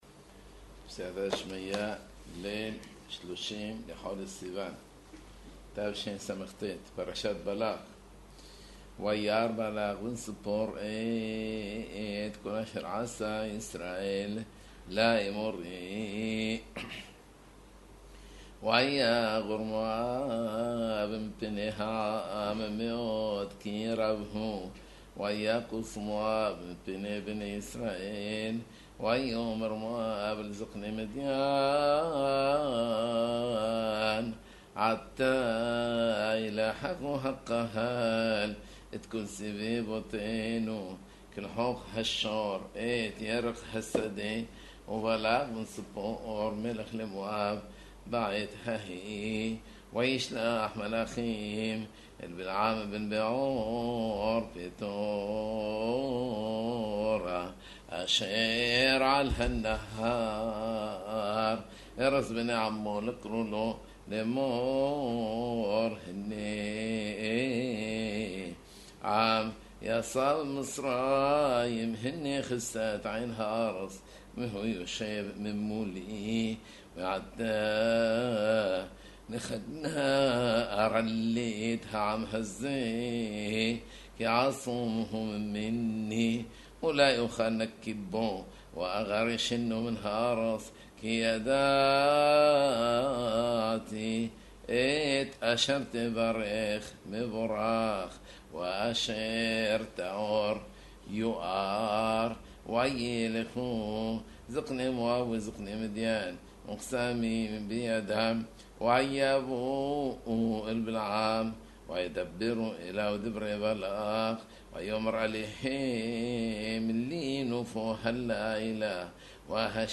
קריאת פרשת השבוע בטעמיה ודיקדוקיה כולל קריאת ההפטרה